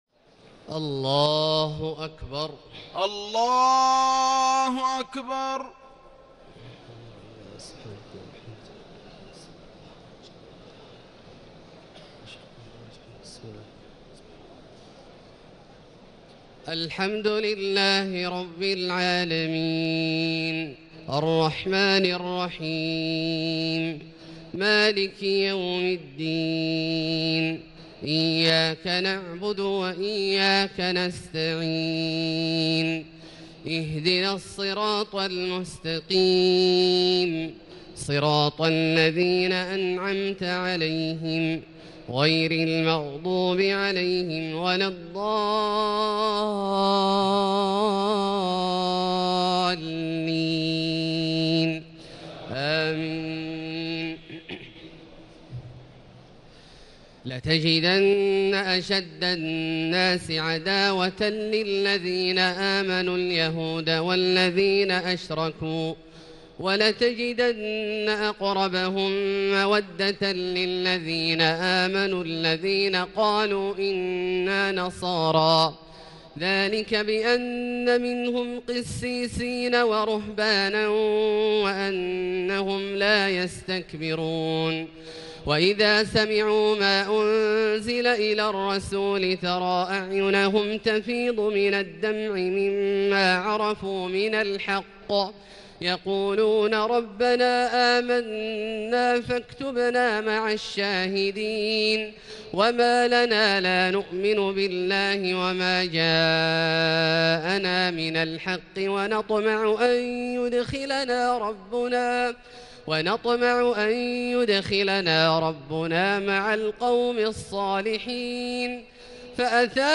تهجد ليلة 27 رمضان 1439هـ من سورتي المائدة (82-120) و الأنعام (1-58) Tahajjud 27 st night Ramadan 1439H from Surah AlMa'idah and Al-An’aam > تراويح الحرم المكي عام 1439 🕋 > التراويح - تلاوات الحرمين